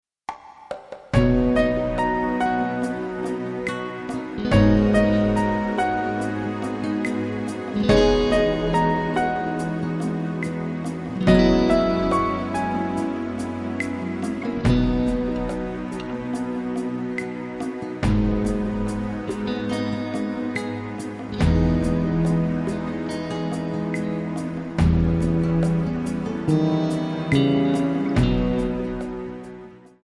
Backing track files: Musical/Film/TV (484)
duet